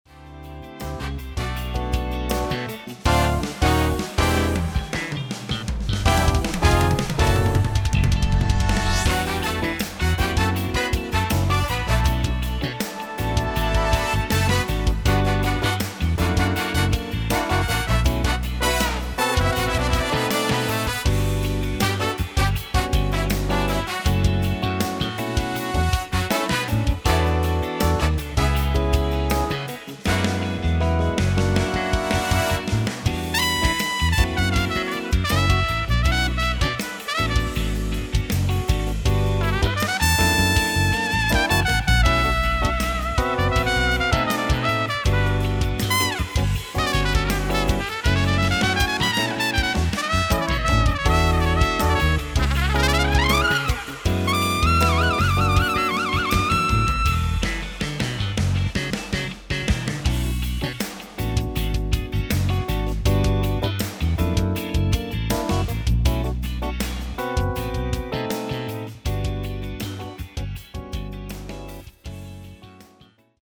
Кусочек припева и коды.
Была задача полностью сымитировать игру живаго. Набор инструментов минимум. Барабасы, бас, родес, две гитары и дутки. Пока без вокала и бэков.